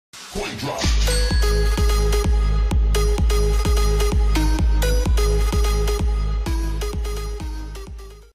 Здесь вы найдете забавные, мотивирующие и необычные аудиоэффекты, которые можно использовать при получении донатов.